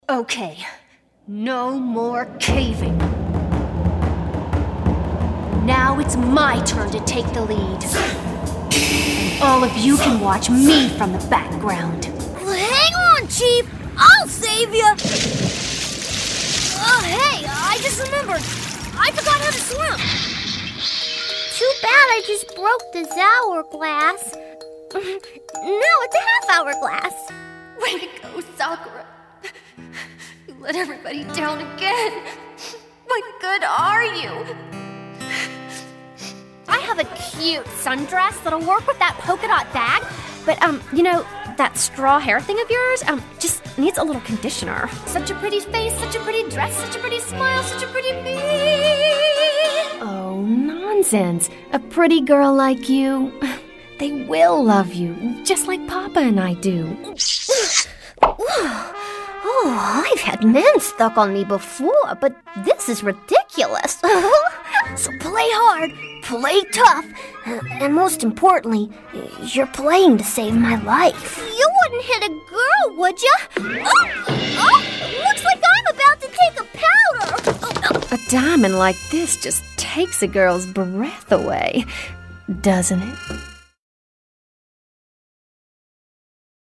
Voiceover
Animation.mp3